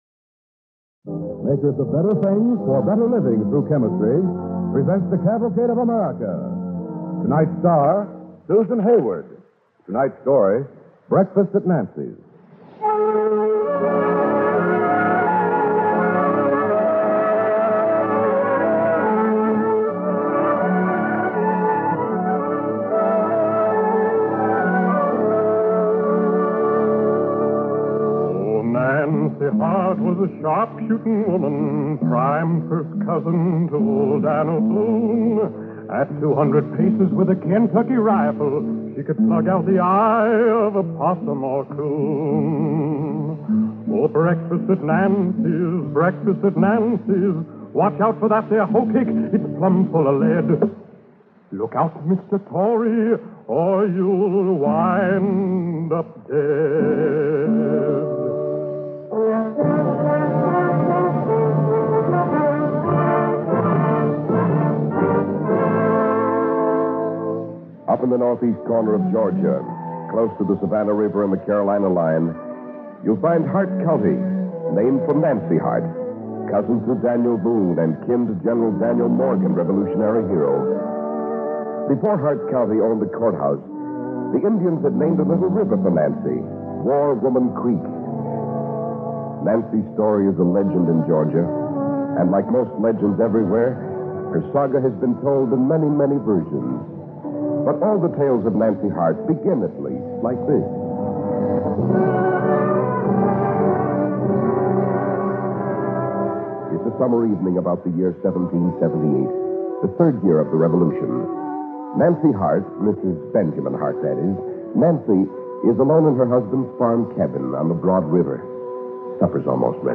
Cavalcade of America Radio Program
Breakfast at Nancy's, starring Susan Hayward and Staats Cotsworth